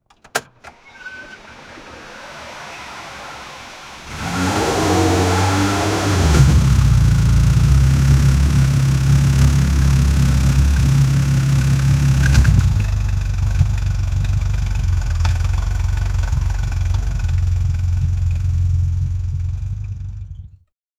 1969-ford-mustang-start-u-sqgjcgsp.wav